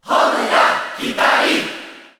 Category: Crowd cheers (SSBU)
Pyra_&_Mythra_Cheer_Japanese_SSBU.ogg